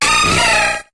Cri d'Azumarill dans Pokémon HOME.